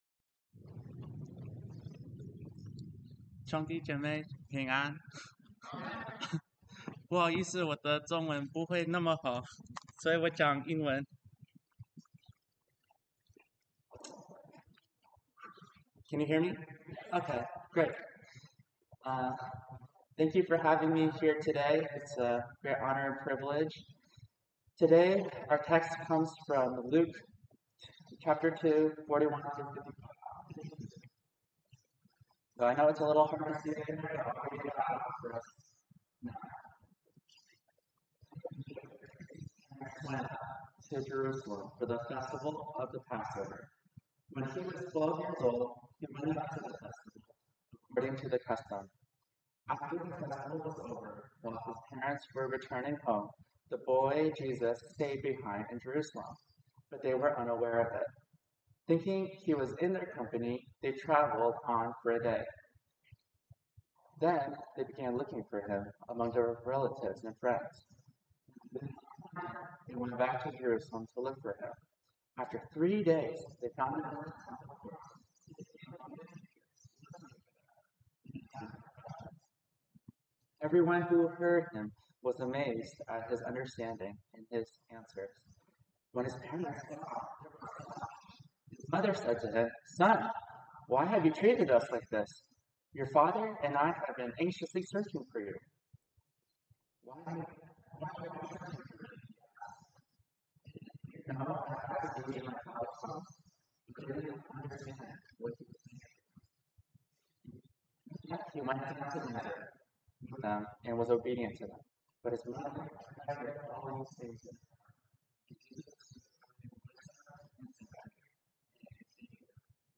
We record live from The Castro Country Club in San Francisco.